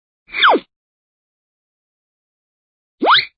Звуки мультяшных персонажей
Звук в мультфильме: незаметная магия